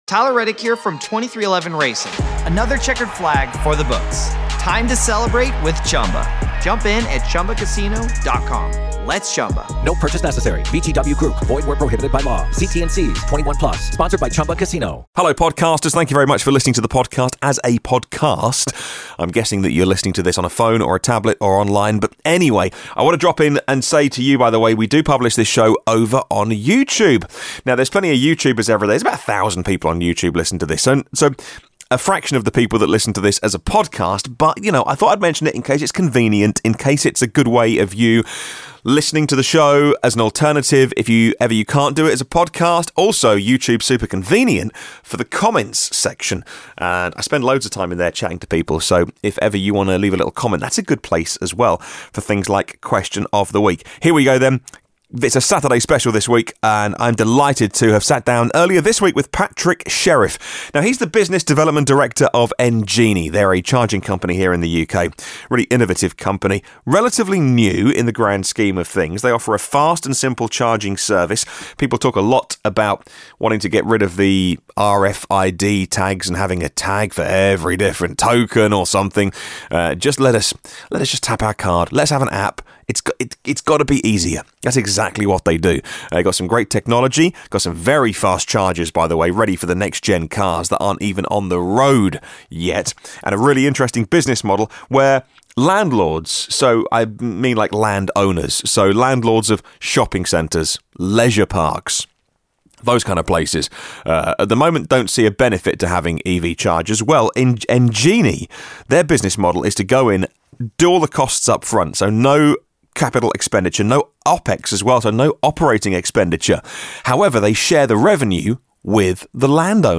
17 Nov 2018 | Interview: Engenie